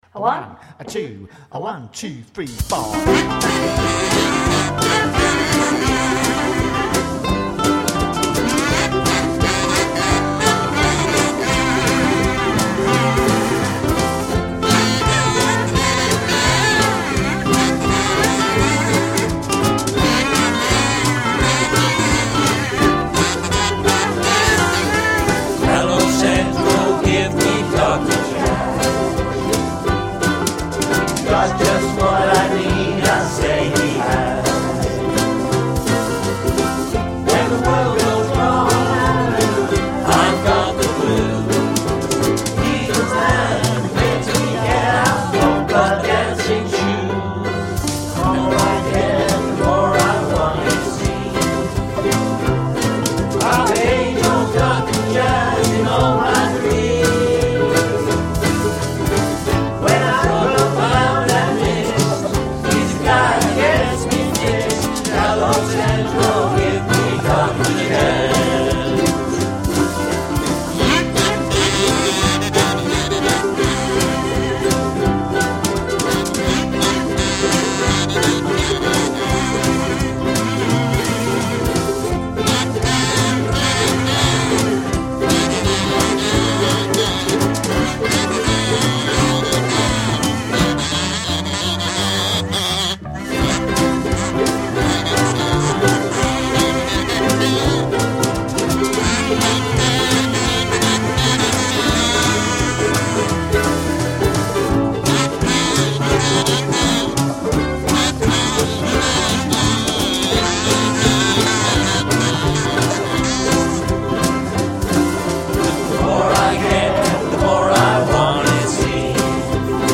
using mobile phones or whatever technology came to hand.